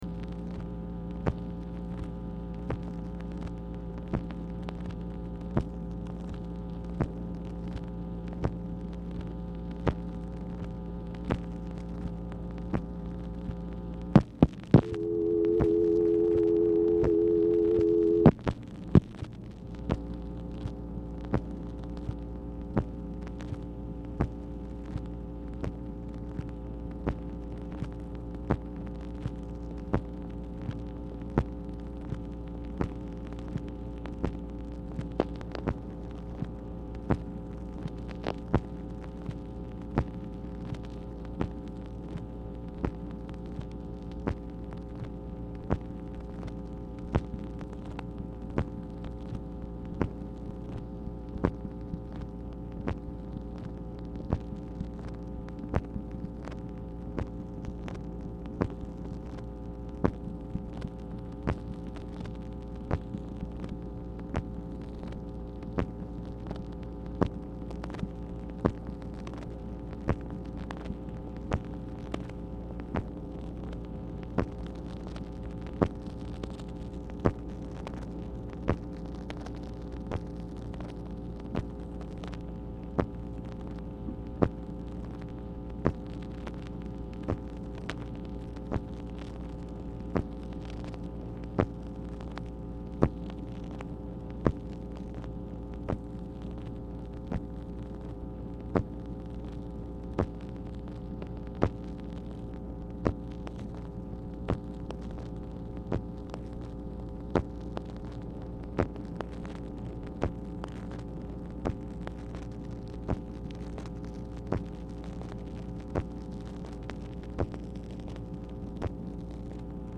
Telephone conversation # 10919, sound recording, MACHINE NOISE, 10/3/1966, time unknown | Discover LBJ
Format Dictation belt